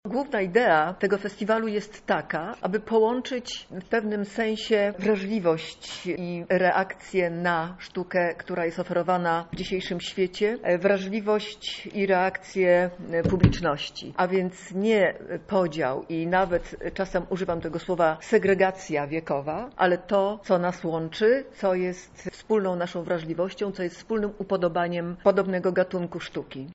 Dżwięki słów – zapo z konfy